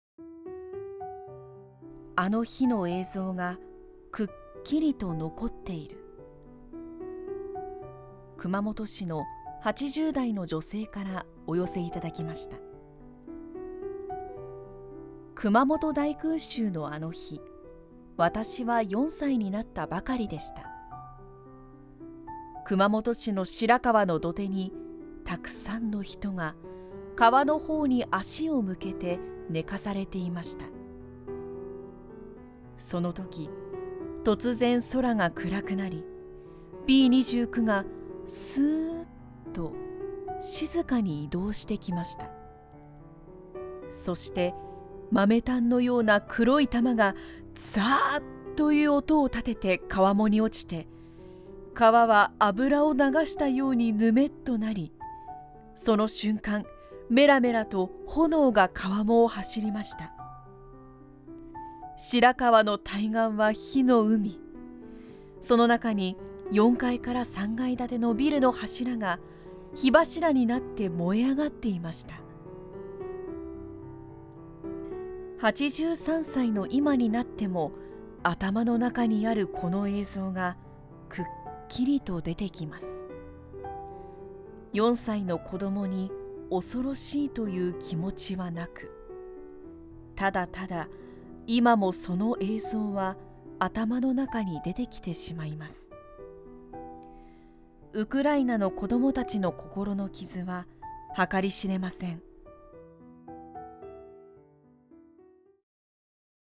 熊本市 女性(80代)